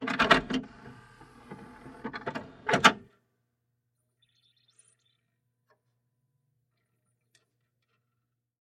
Звуки CD-плеера
Замена диска в CD-плеере